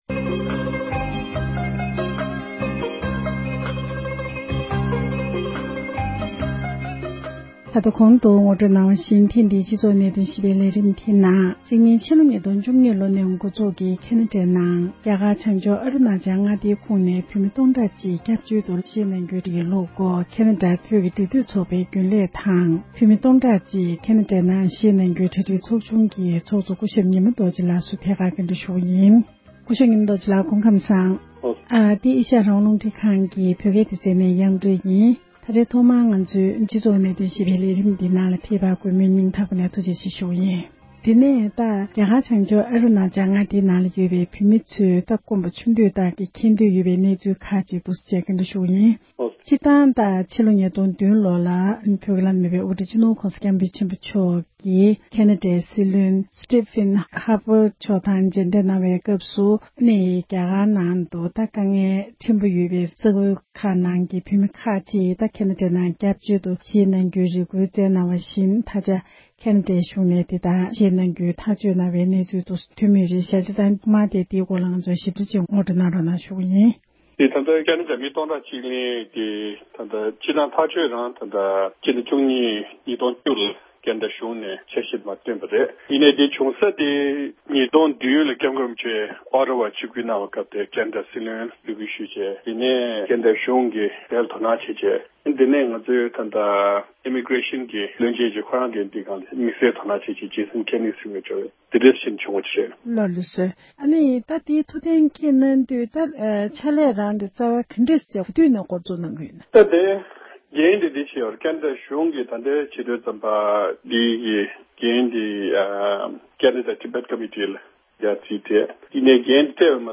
འབྲེལ་ཡོད་མི་སྣ་དང་བཀའ་མོལ་ཞུས་པར་གསན་རོགས་ཞུ༎